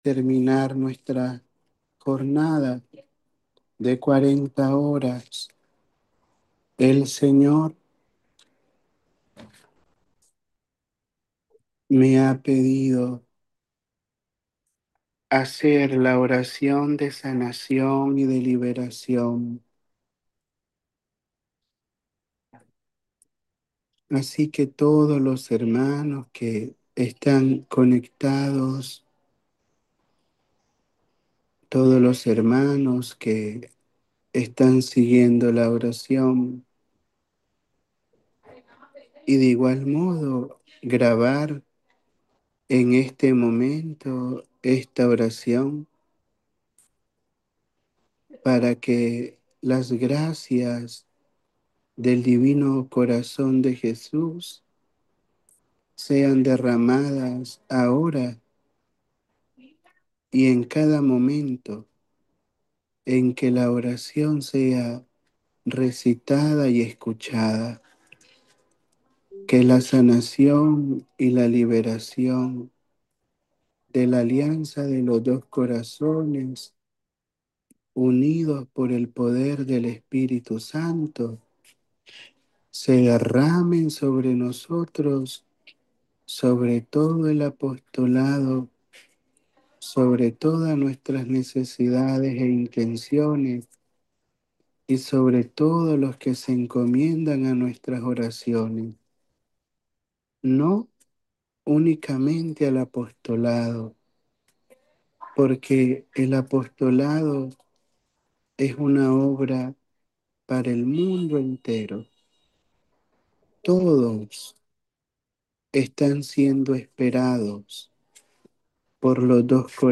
Durante a Jornada de 40 horas de Adoração Eucarística na Solenidade de Corpus Christi.